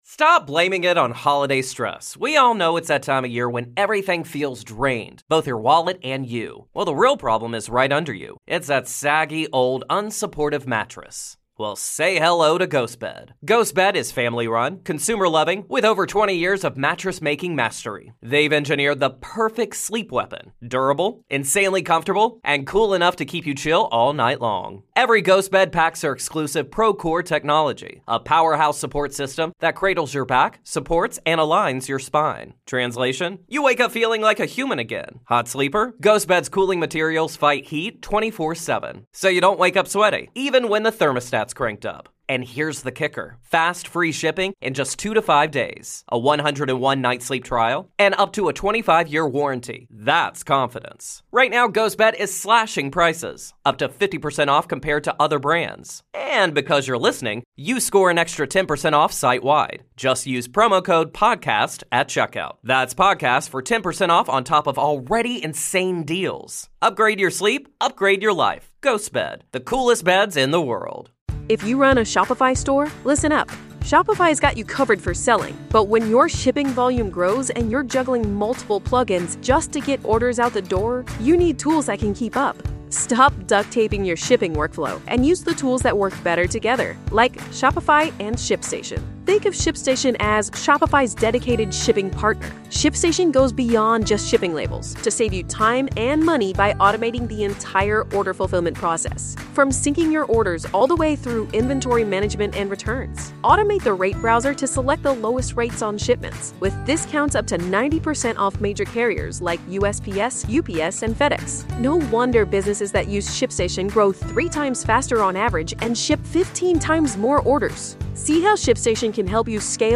If you aren't subscribed to our Patreon, we wanted to give you a glimpse of what we've got going on. This mega-mix of clips brings you some of our favorite moments in recent weeks.